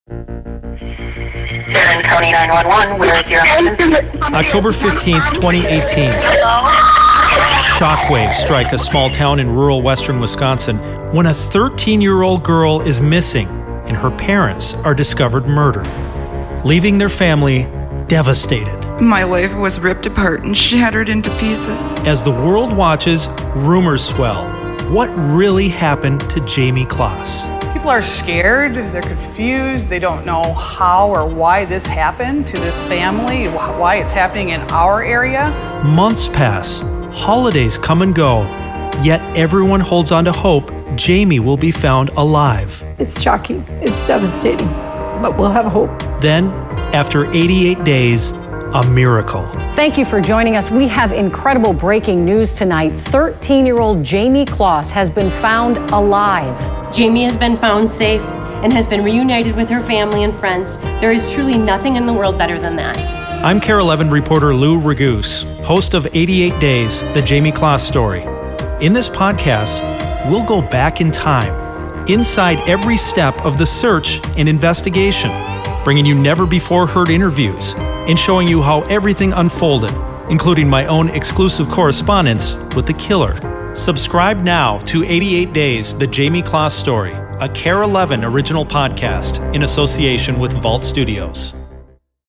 Trailer: